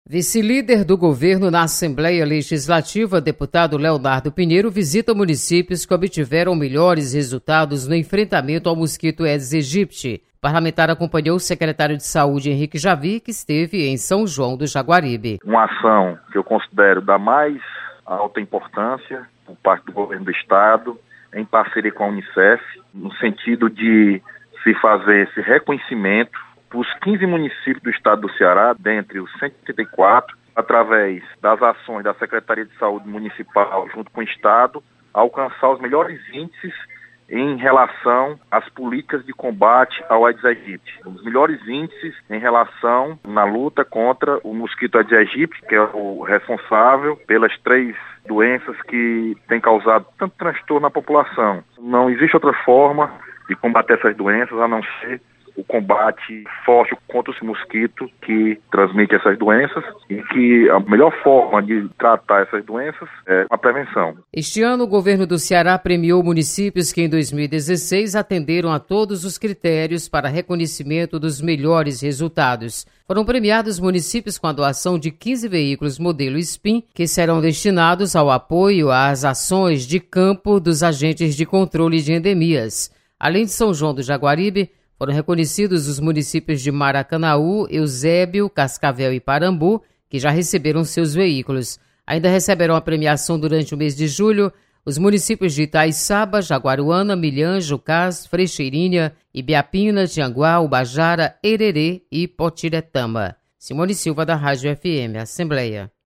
Deputado Leonardo Pinheiro relata sobre combate ao Aedes aegypti.